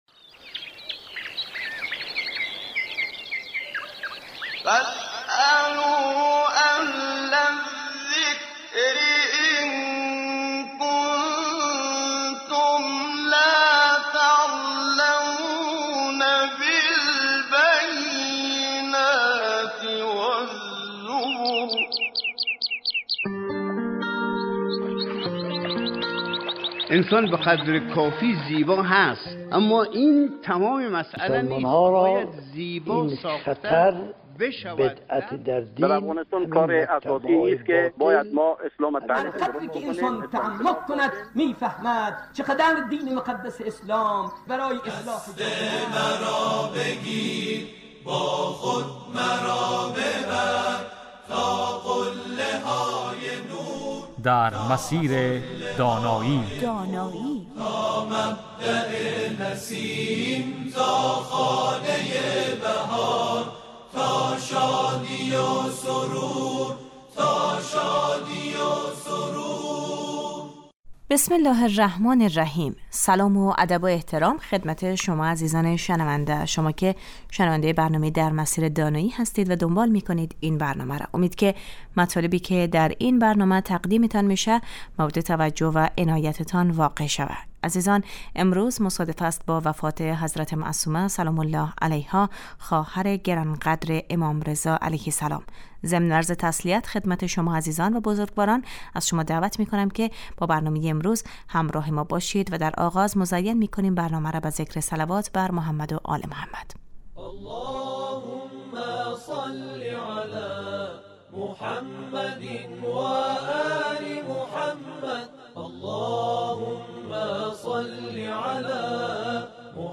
در این برنامه در سه روز اول هفته در مورد تعلیم تربیت کودک و نوجوان از دیدگاه اسلام و در سه روز پایانی هفته در مورد مسایل اعتقادی و معارف اسلامی بحث و گفتگو می شود .